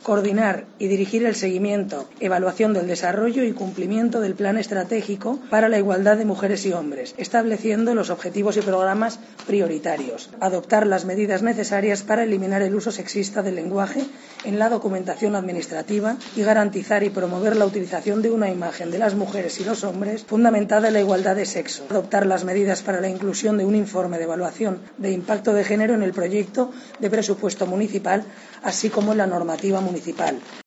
Escucha a Isabel Albás sobre el Plan Transversal de Género